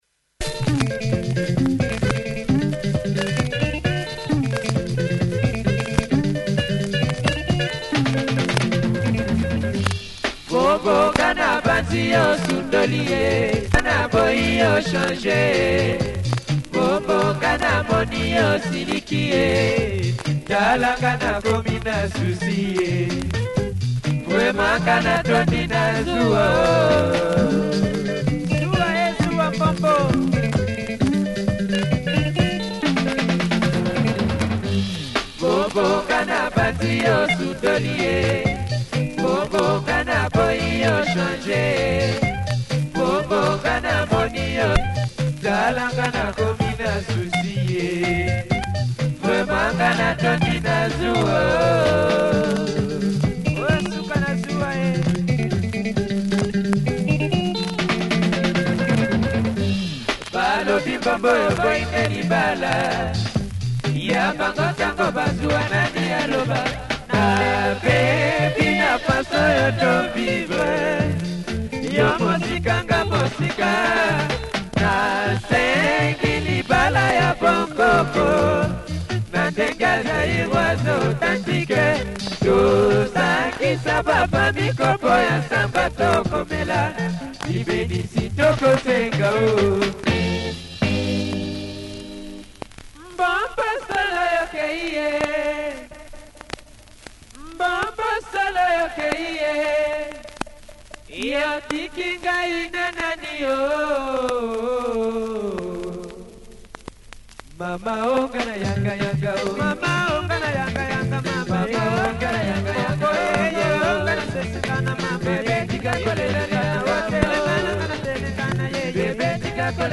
Good production, pumping horns and superb guitars